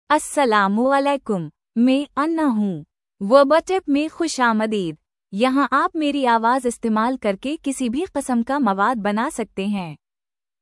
FemaleUrdu (India)
AnnaFemale Urdu AI voice
Anna is a female AI voice for Urdu (India).
Voice sample
Listen to Anna's female Urdu voice.